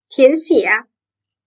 tiánxiě - thén xỉa Điền